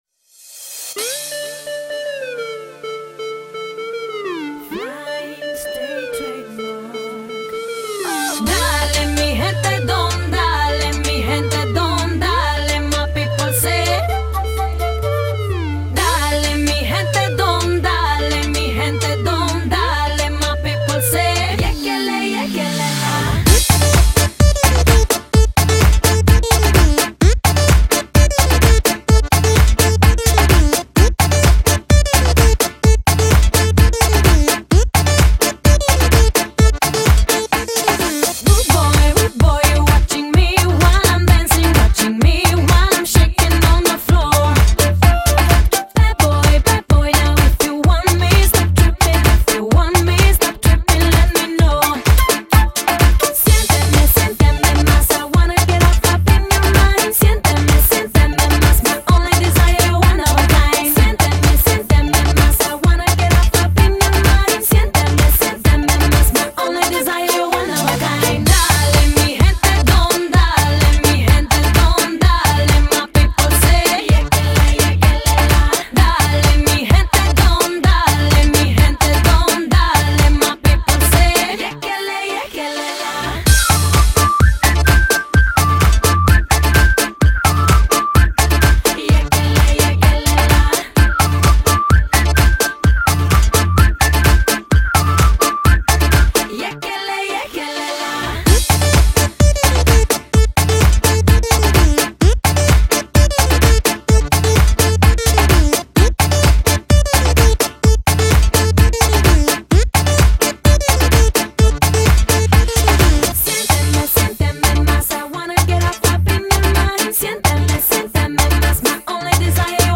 Дискотечная песня